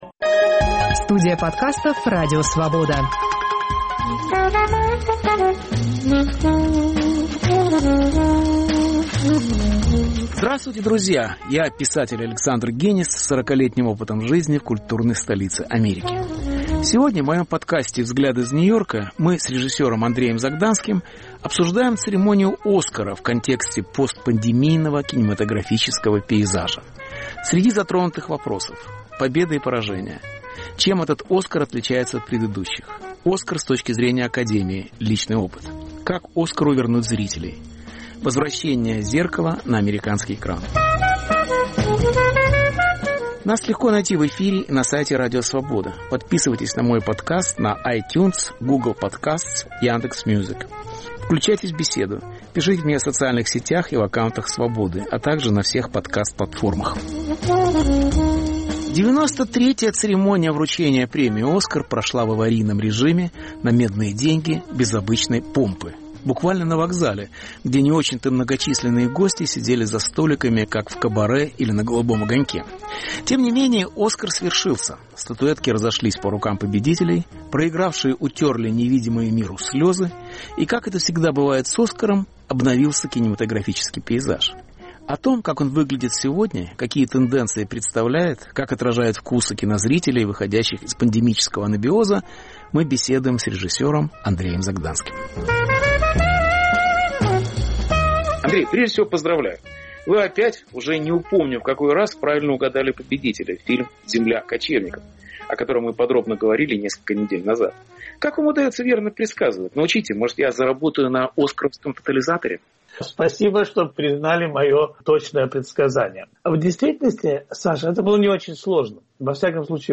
Беседа